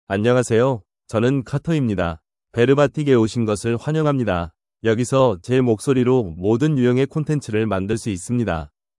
MaleKorean (Korea)
CarterMale Korean AI voice
Carter is a male AI voice for Korean (Korea).
Voice sample
Listen to Carter's male Korean voice.
Male